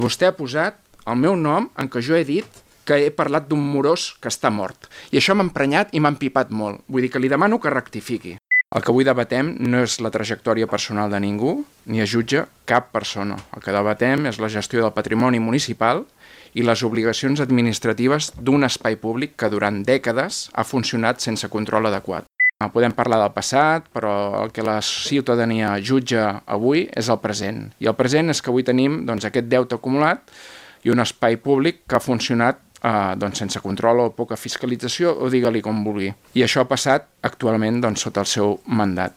Debat tens en l’última sessió plenària per les posicions enfrontades entre govern i oposició sobre la gestió del bar El Paso, que posarà punt final a la seva activitat amb el canvi d’any.